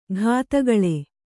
♪ ghātagaḷe